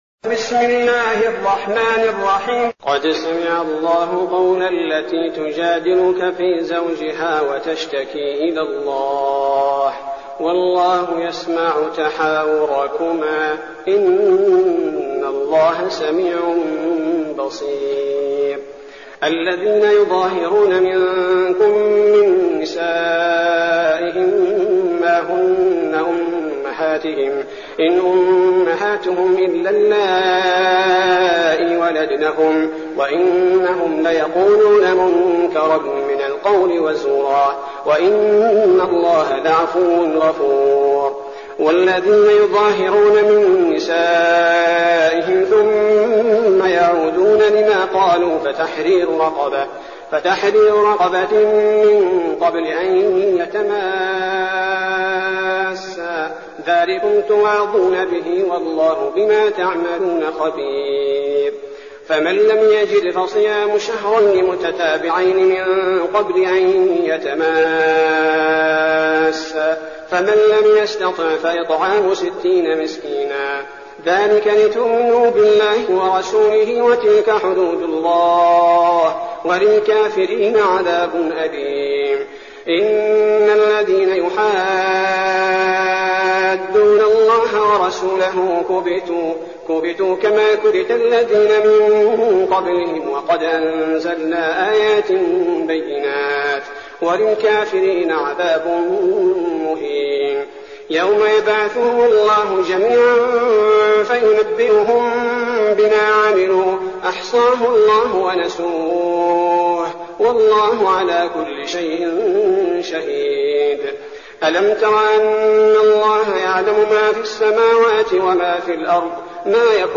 المكان: المسجد النبوي الشيخ: فضيلة الشيخ عبدالباري الثبيتي فضيلة الشيخ عبدالباري الثبيتي المجادلة